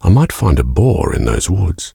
B_Boar.ogg